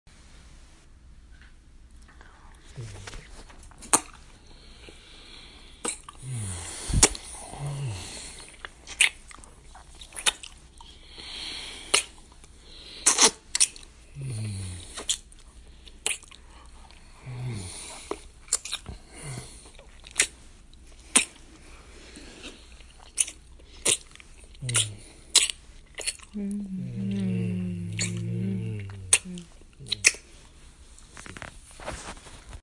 Download Making Out sound effect for free.